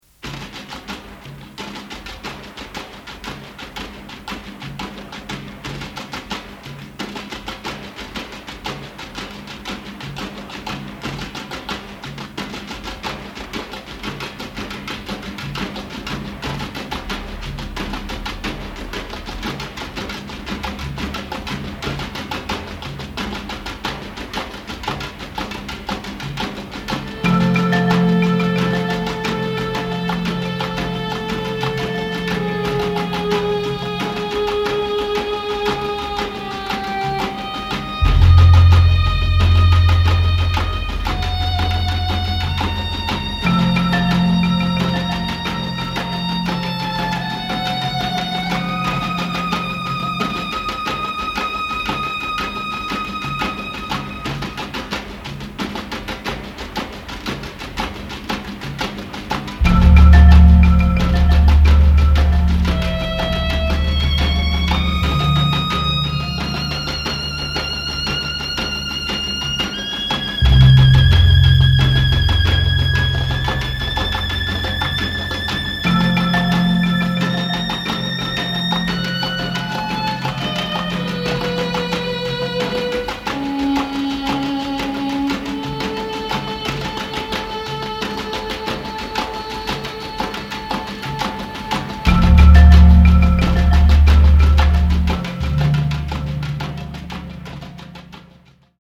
キーワード：ミニマル　サウンドスケープ　空想民俗